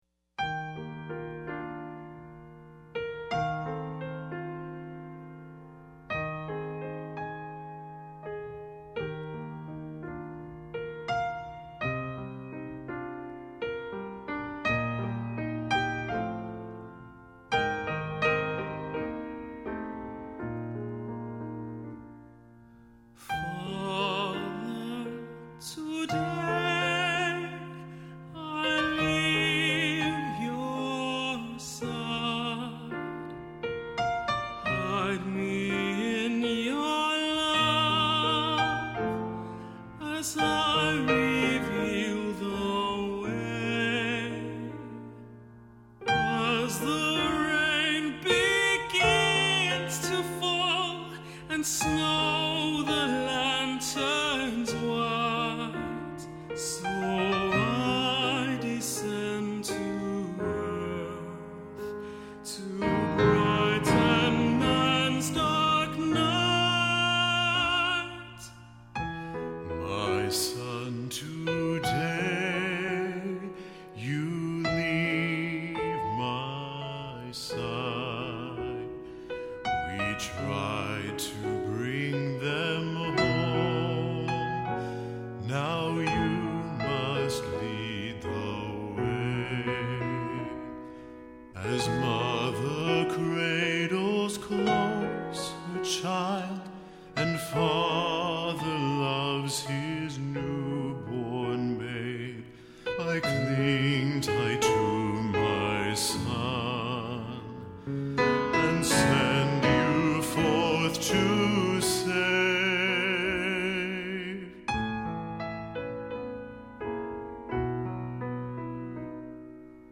Arranger: Tb Duet With Satb
Voicing: SATB